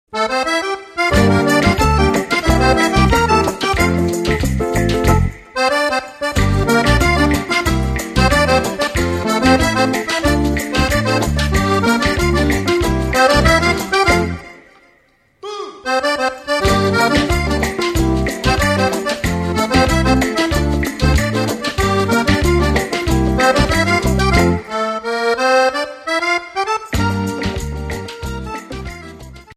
Ballabili Sudamericani
Bayon